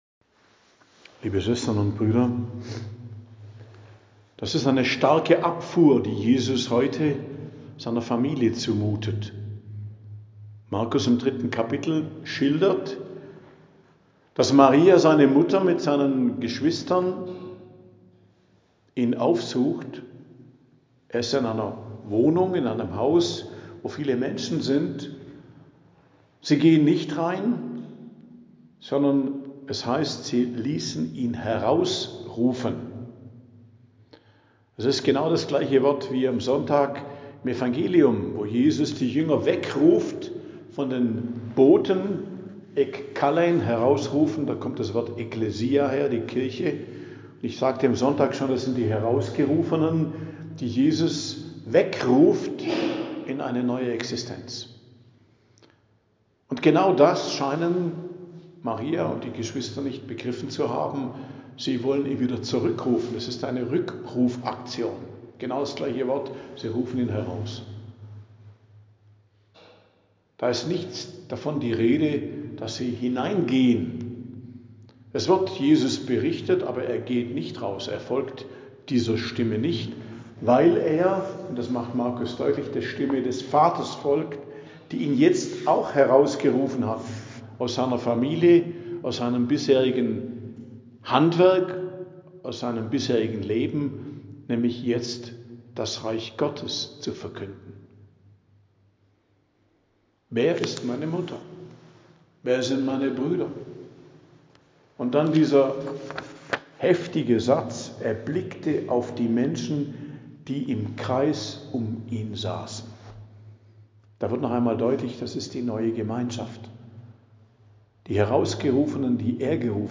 Predigt am Dienstag der 3. Woche i.J., 27.01.2026